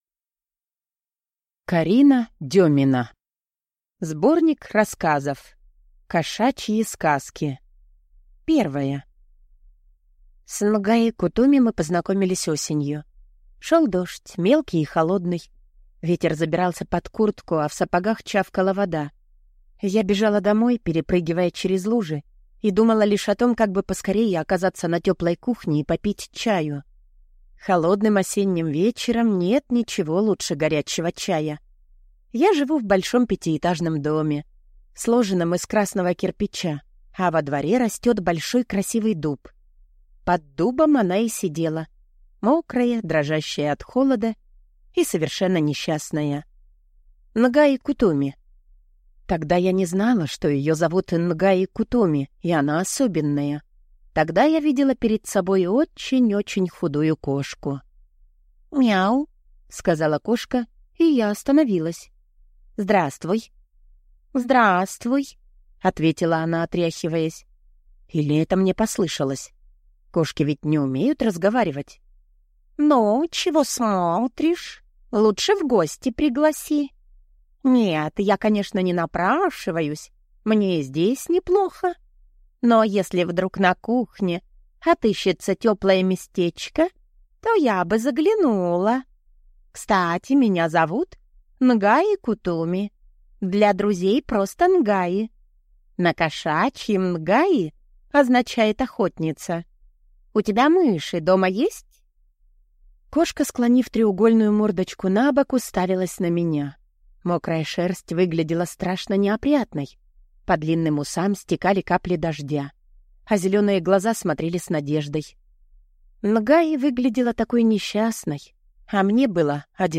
Аудиокнига Рассказы | Библиотека аудиокниг